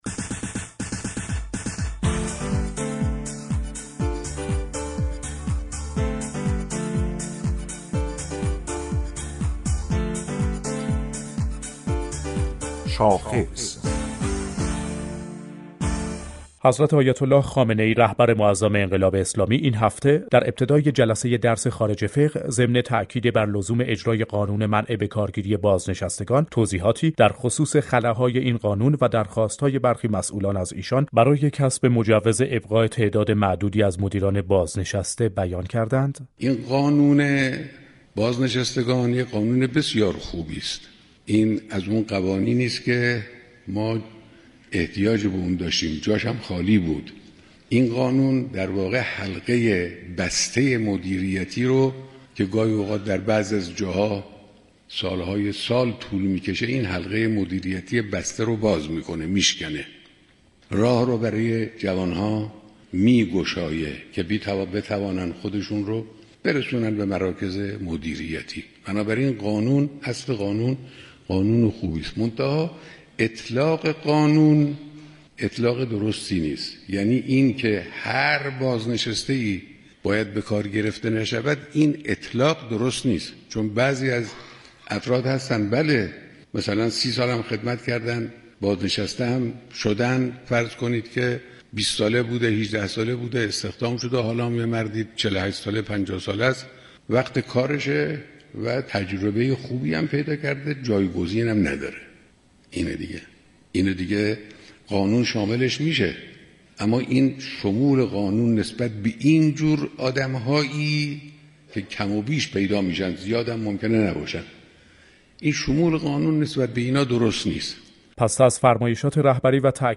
محمدعلی پورمختار عضو كمیسیون حقوقی قضایی مجلس در بخش شاخص برنامه رویدادهای هفته رادیو ایران